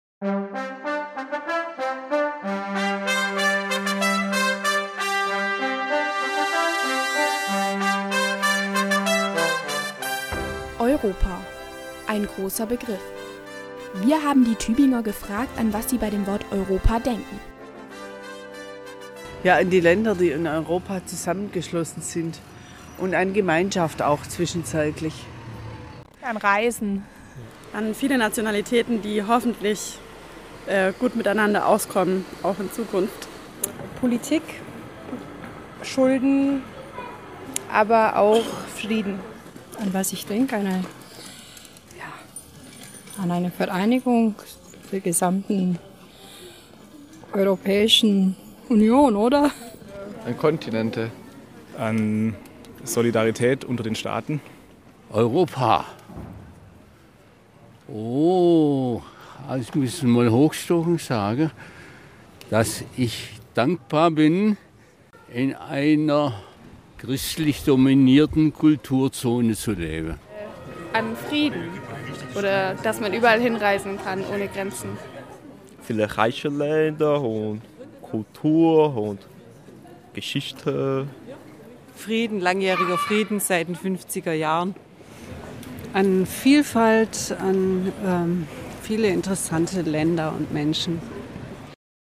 Gedanken zu Europa - Eine Umfrage
Die Jugendredaktion YouEdiT hat nachgefragt.
Gedankensammlung zu Europa, zusammengetragen aus den Interviews in Tübingen: